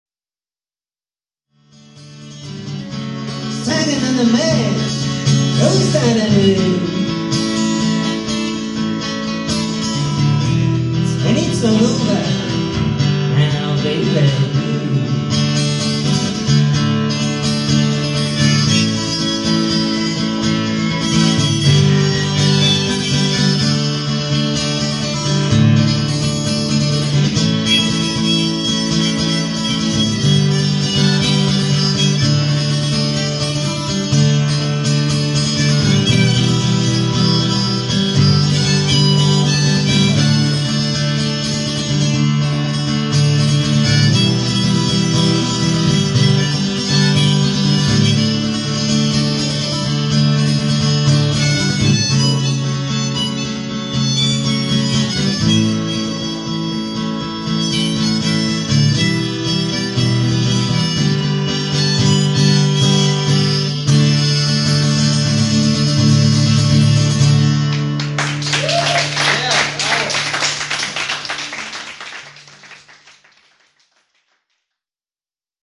Malga Ime (Verona - Italy)